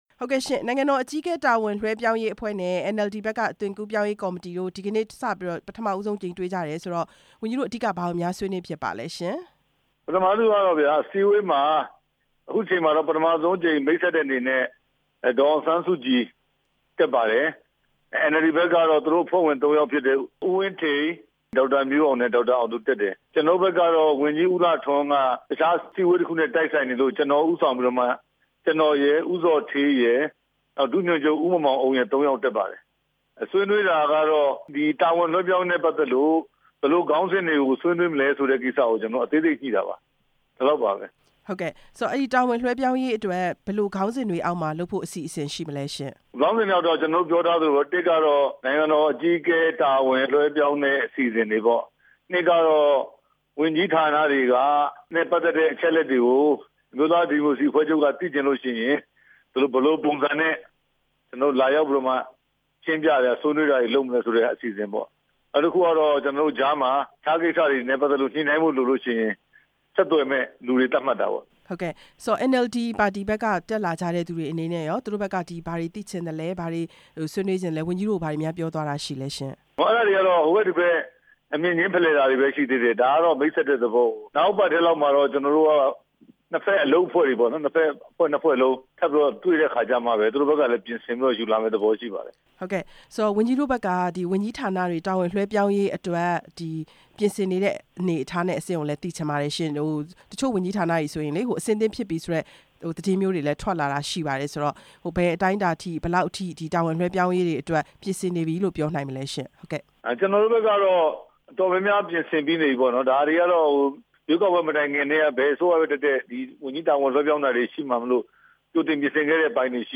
ဝန်ကြီး ဦးရဲထွဋ် ကို မေးမြန်းချက်